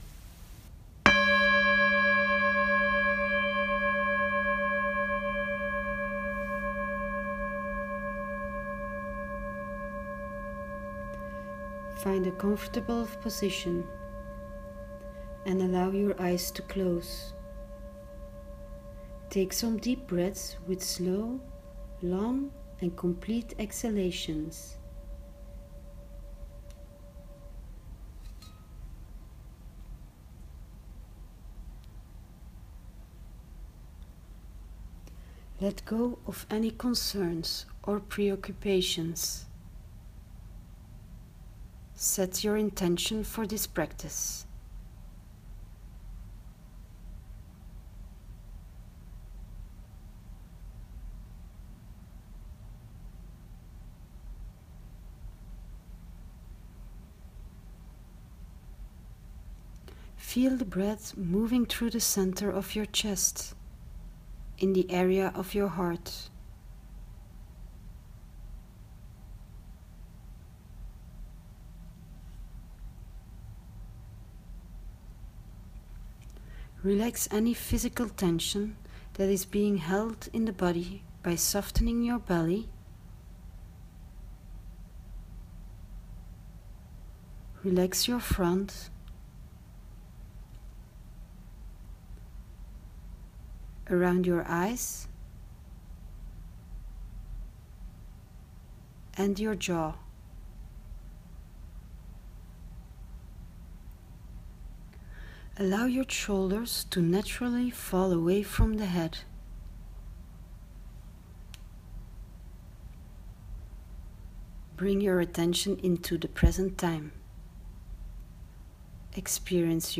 You’ll find the guided meditation as a voice recording under the screen with the introduction video.